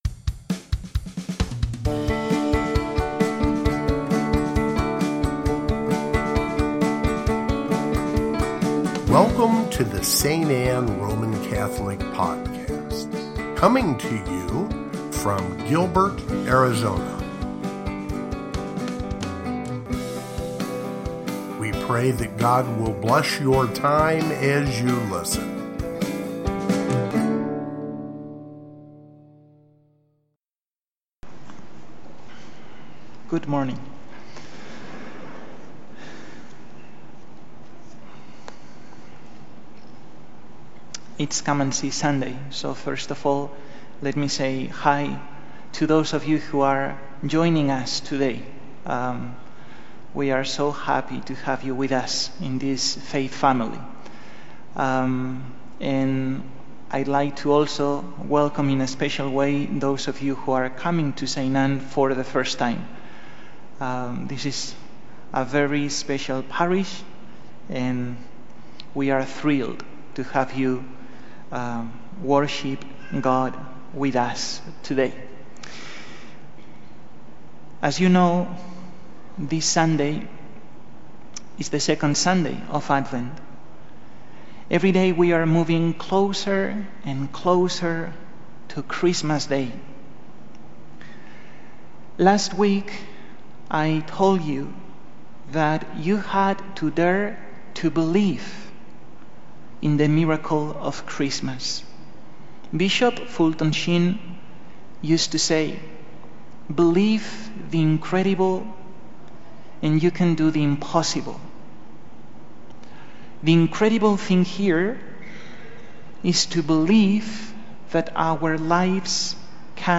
Second Sunday of Advent (Homily) | St. Anne